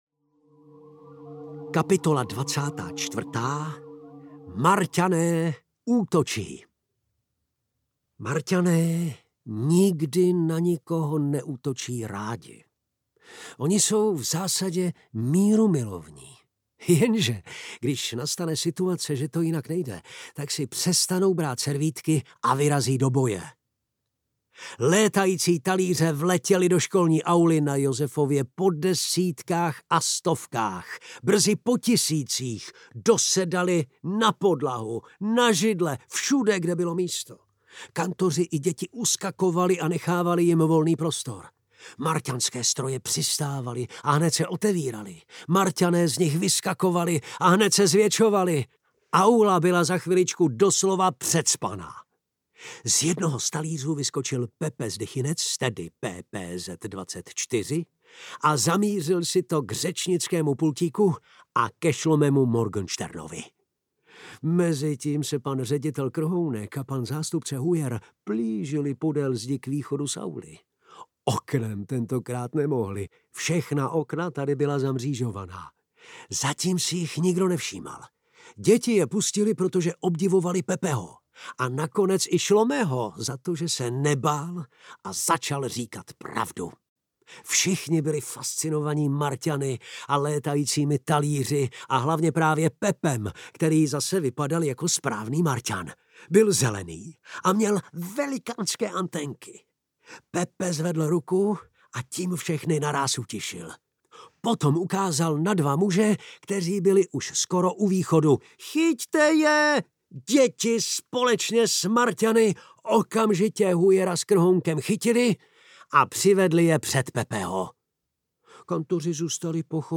Ukázka z knihy
martanek-pepe-audiokniha